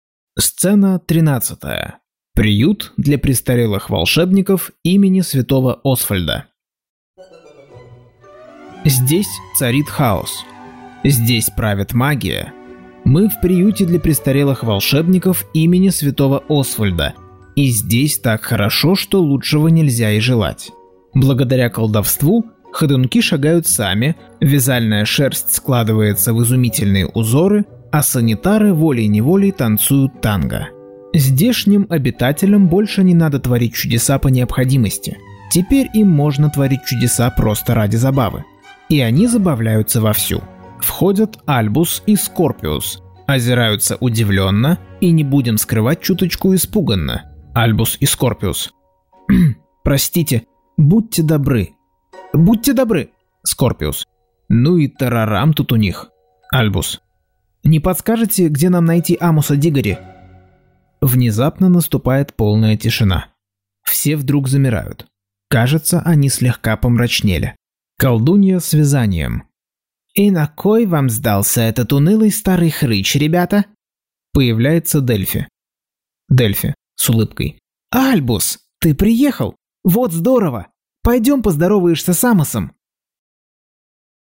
Аудиокнига Гарри Поттер и проклятое дитя. Часть 10.